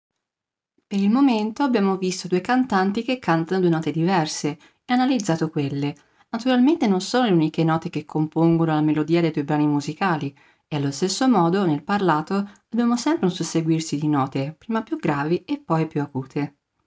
In questo audio potete sentire la mia voce mentre sto leggendo il paragrafo che avete appena visto.
Qui vedete come l’altezza della mia voce sia un qualcosa di fluido, nel parlato, diversamente nel canto che deve sottostare a regole ben precise per far sì che la voce sia considerata intonata.